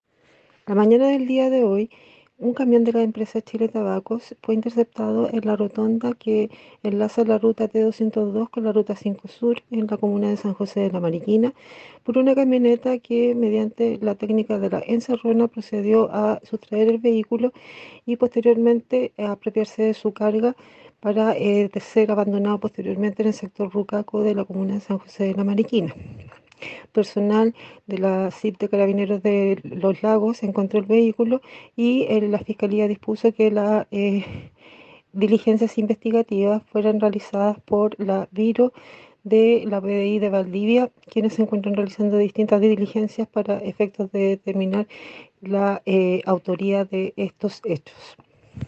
fiscal subrogante de Mariquina, Carmen Gómez, sobre un robo que afectó a un camión de Chiletabacos